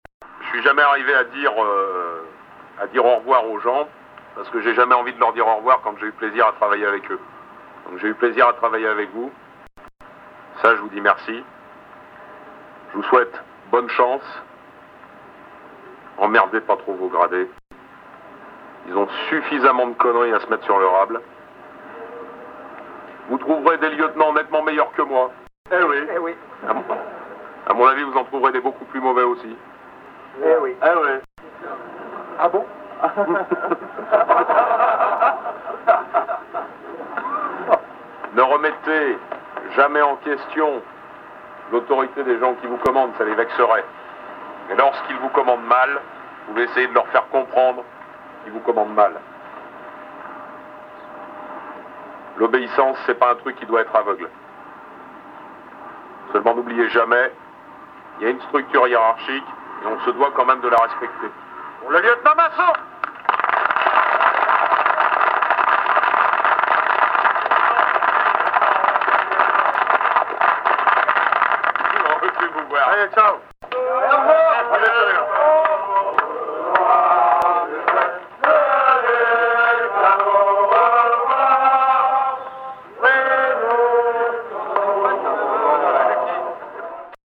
Speech de fin.